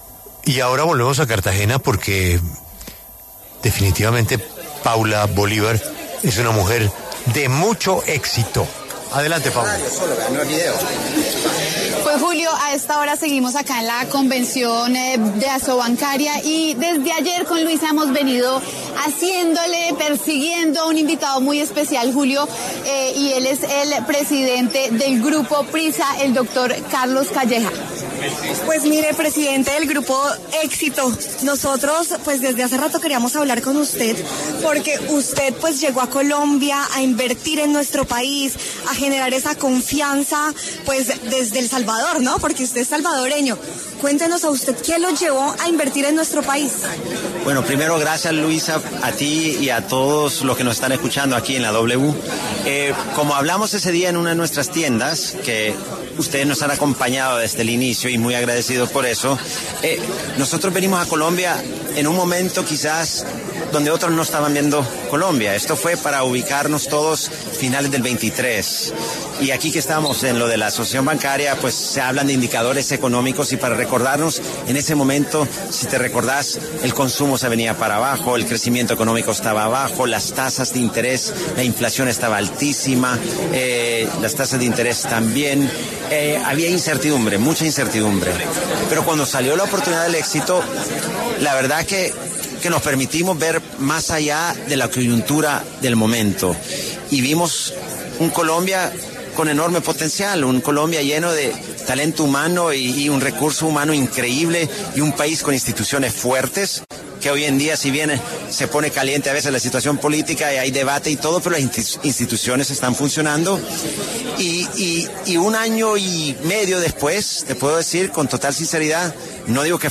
Desde la convención de Asobancaria, Carlos Calleja Hakker, presidente Grupo Éxito, habló en La W, habló sobre las inversiones que hizo en el país destacando que llegaron a Colombia en un momento donde quizás otros no lo estaban viendo.